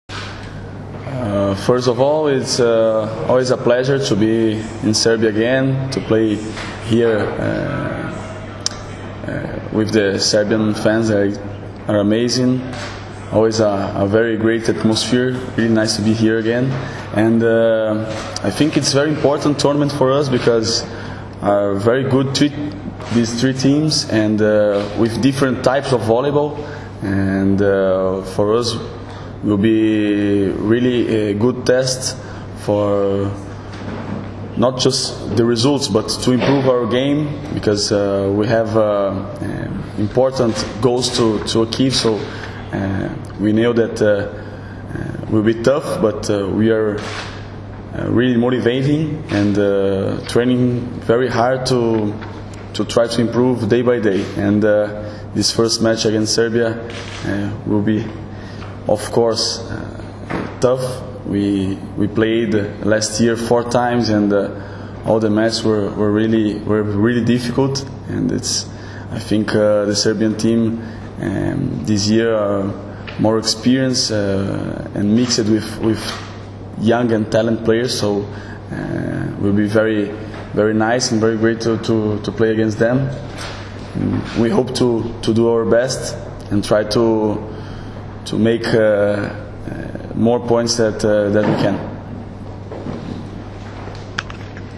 U beogradskom hotelu “M” danas je održana konferencija za novinare povodom turnira F grupe XXVII Svetske lige 2016, koji će se odigrati u dvorani “Aleksandar Nikolić” u Beogradu od 23. – 25. juna.
IZJAVA BRUNA REZENDEA